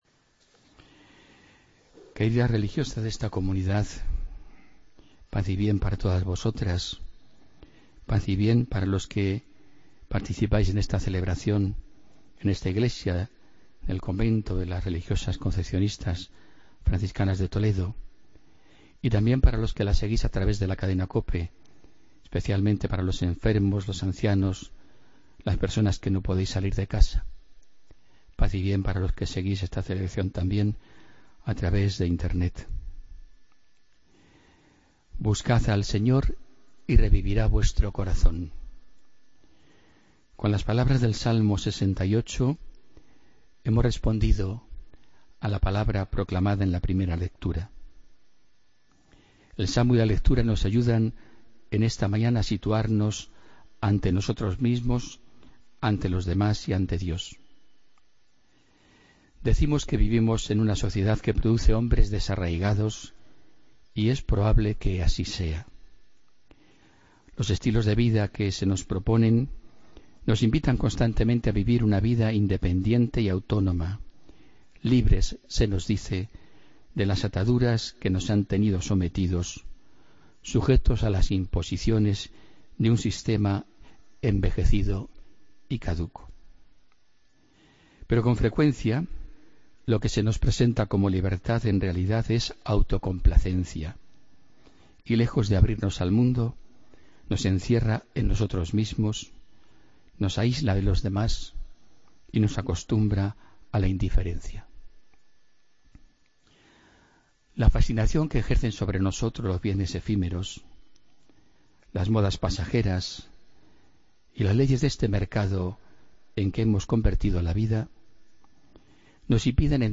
Homilía del domingo 10 de julio de 2016